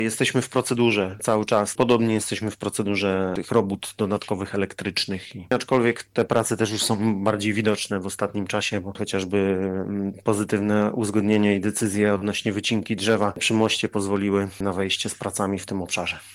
I ten jednak nie zostanie dotrzymany, a wiceburmistrz Piotr Wolny tłumaczy, że po wykryciu kolizji między innymi starej sieci gazowej trzeba było ogłaszać dodatkowe przetargi na ich usunięcie.